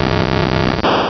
Cri de Spectrum dans Pokémon Rubis et Saphir.